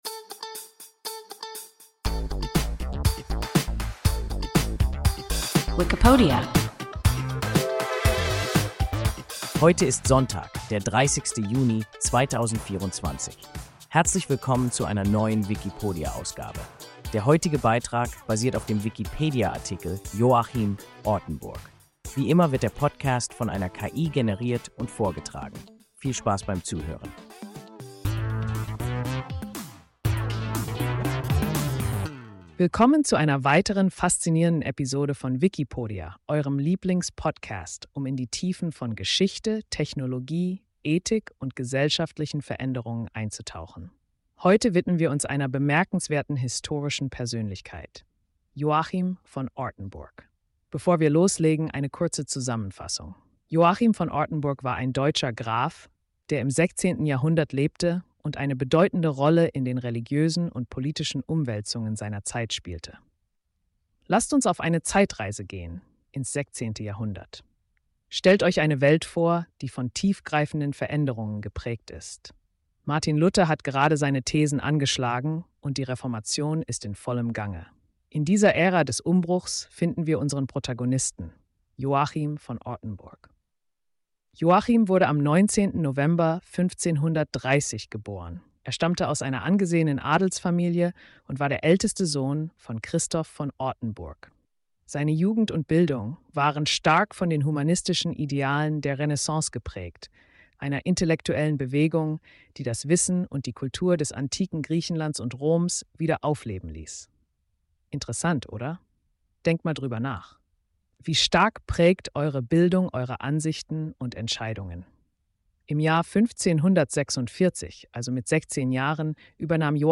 Joachim (Ortenburg) – WIKIPODIA – ein KI Podcast